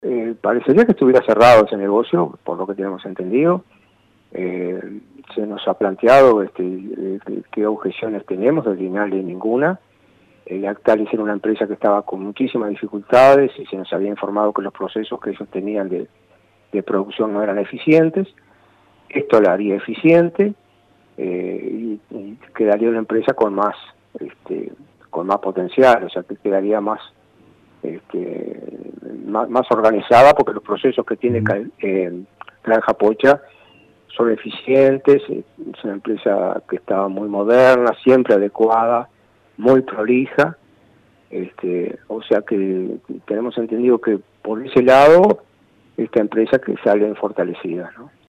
Ricardo de Izaguirre, presidente del Instituto Nacional de la Leche (INALE) confirmó la noticia y dijo que, desde este organismo, no se puso ninguna objeción para que se realice esta transacción.